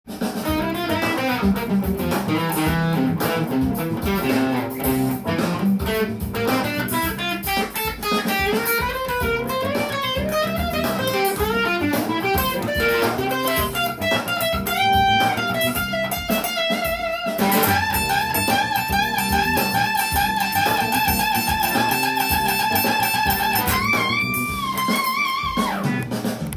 ポジション移動し　カンタンにギターソロを盛り上げることができます。
C7　Funk風ギターソロ